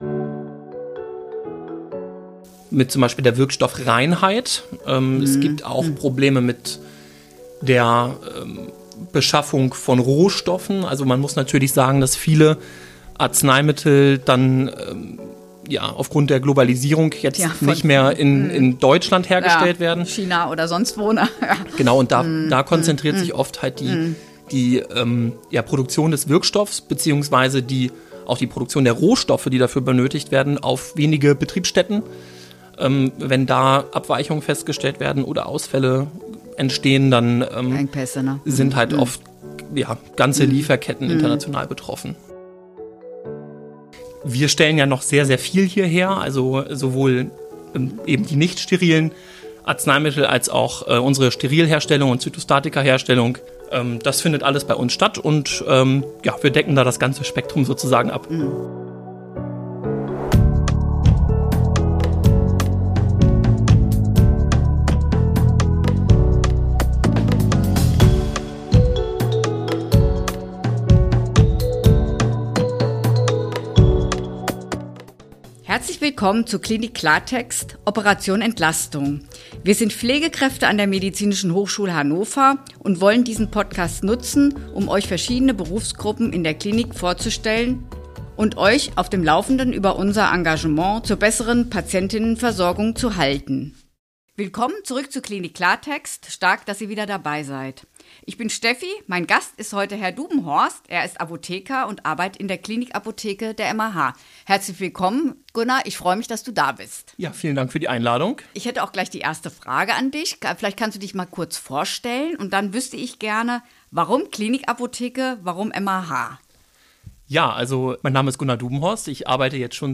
Apotheke – Ein Interview